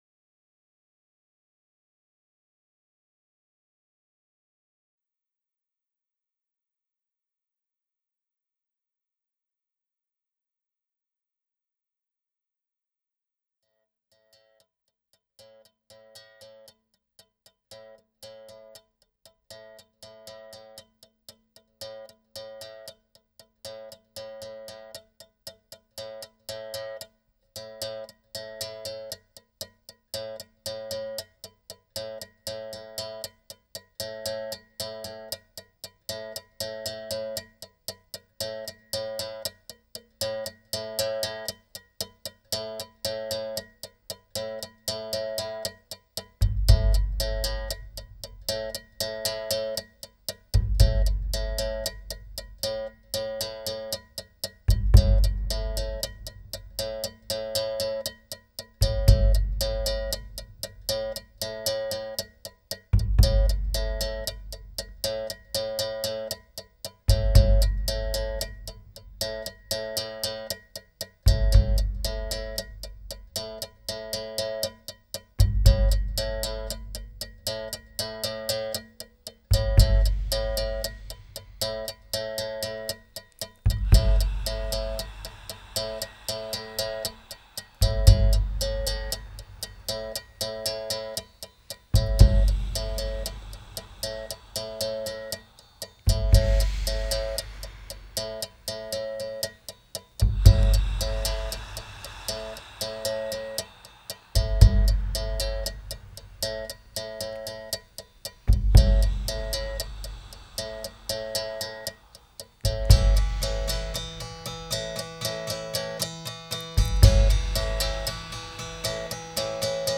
totally hypnotic one-hour meditation